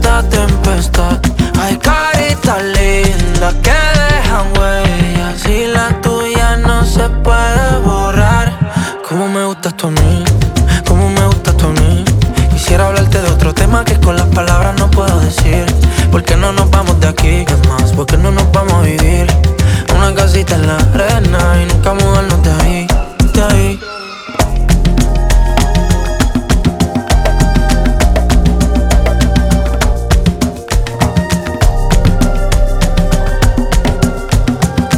Latin Pop